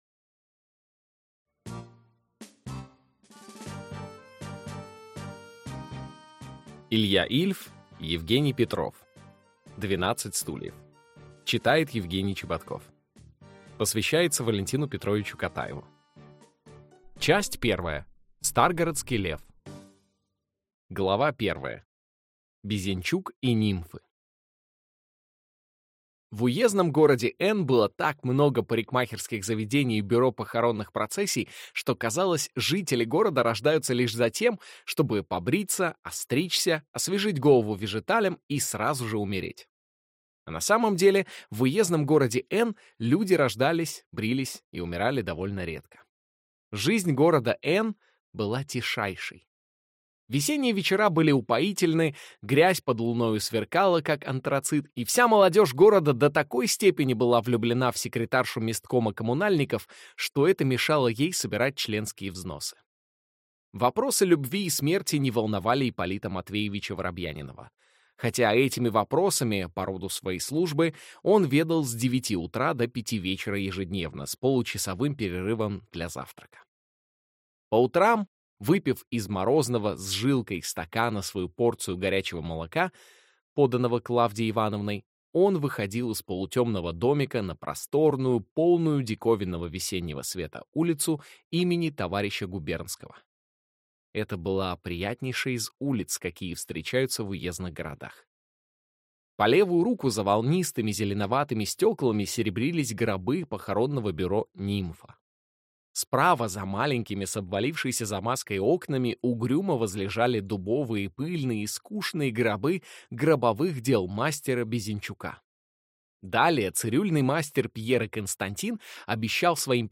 Аудиокнига Двенадцать стульев | Библиотека аудиокниг